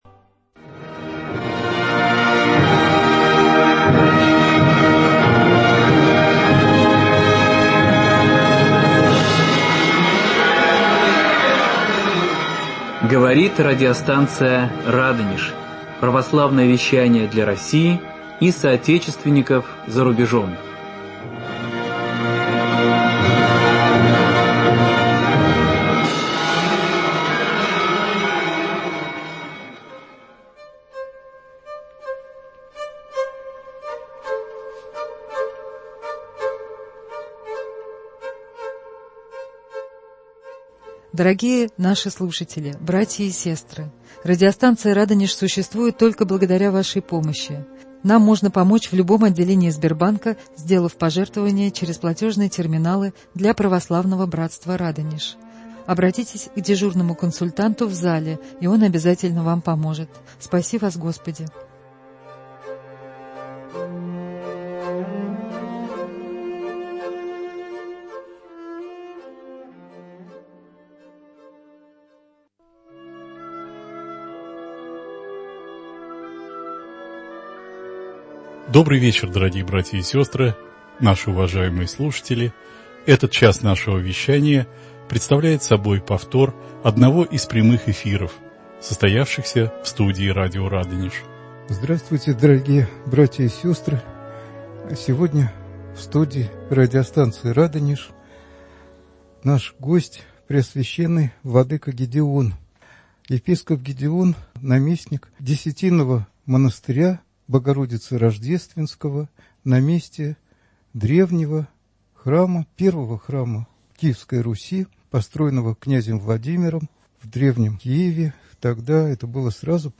Беседа с преосвященным епископом Гедеоном (Харон).